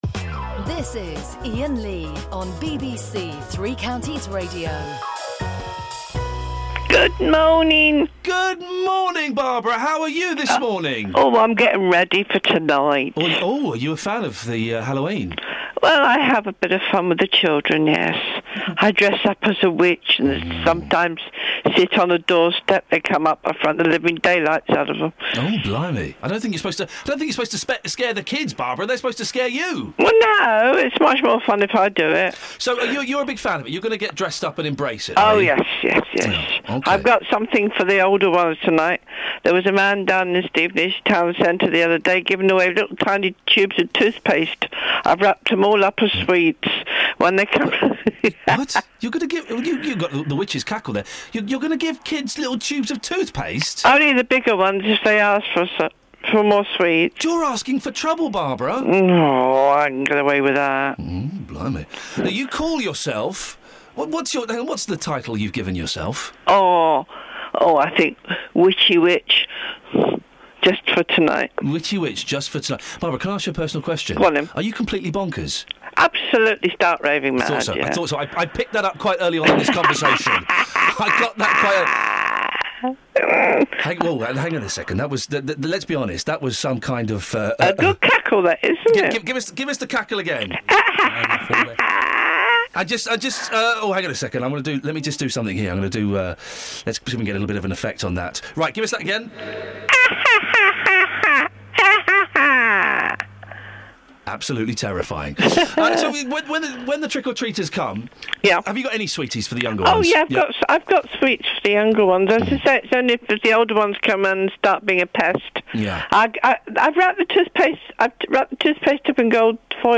INFECTIOUS CACKLE!